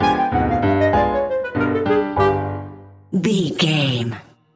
Aeolian/Minor
flute
oboe
strings
circus
goofy
comical
cheerful
perky
Light hearted
quirky